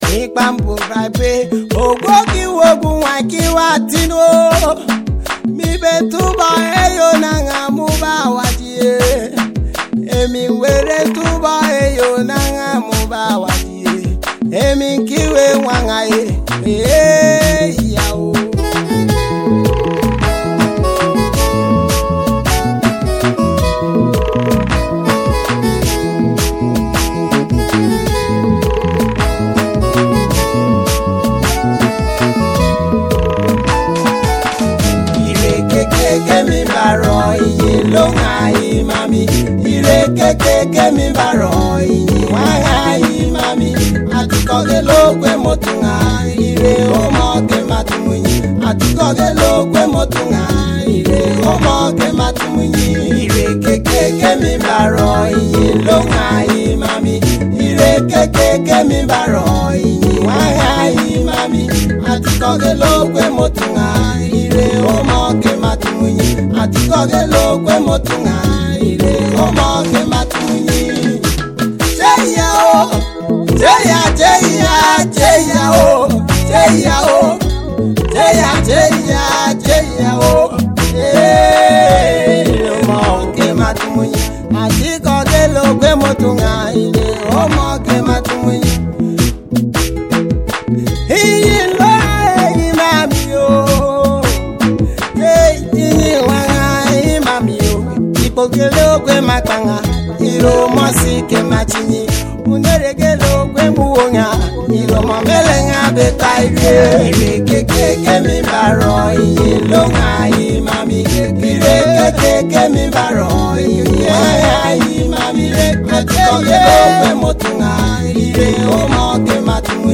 Nigeria Gospel highlifes